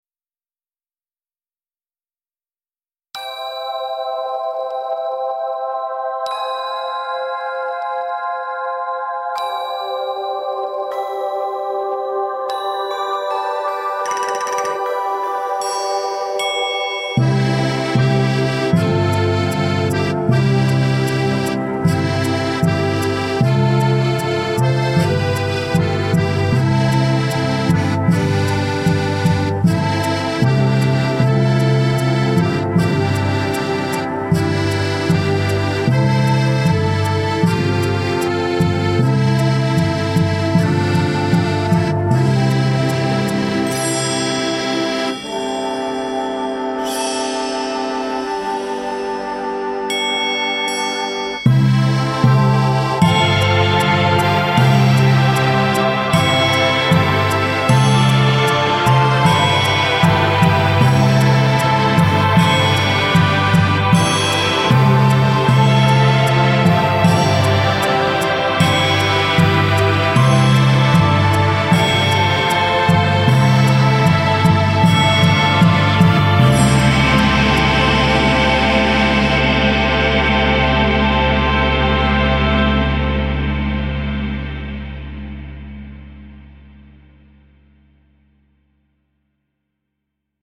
4t – Flauta
La nova peça que estem treballant es diu senzillament “Melodia”, tot i que també li dic “Melodia Hobbit” perquè està inspirada en l’inici de la pel·lícula “El Señor de los Anillos”. Aquest és el seu acompanyament: